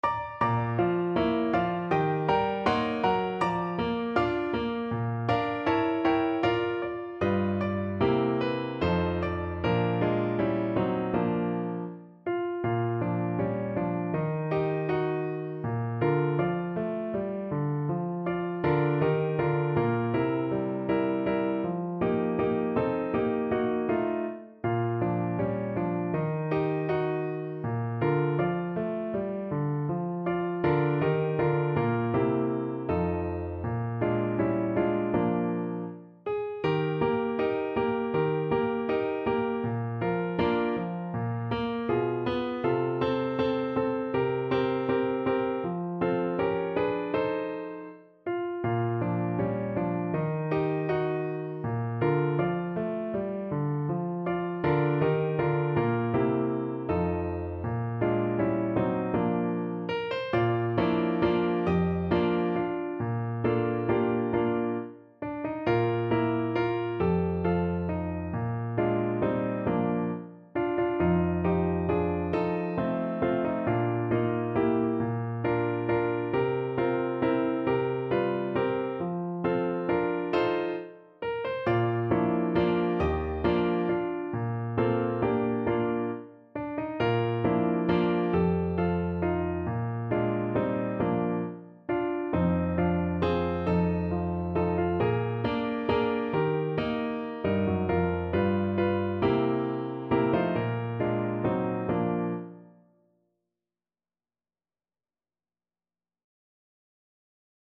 4/4 (View more 4/4 Music)
Andante